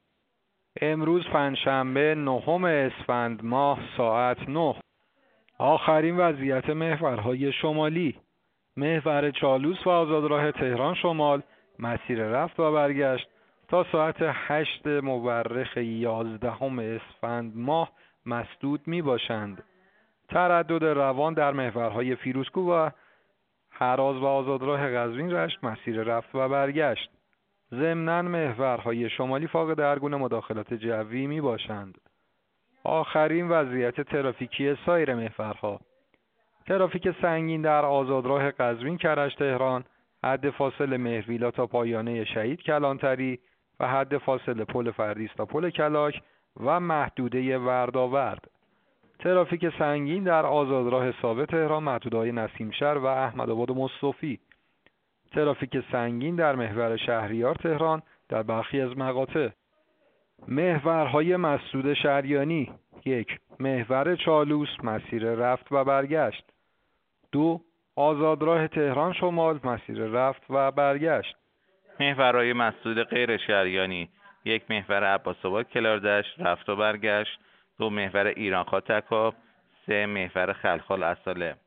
گزارش رادیو اینترنتی از آخرین وضعیت ترافیکی جاده‌ها ساعت ۹ نهم اسفند؛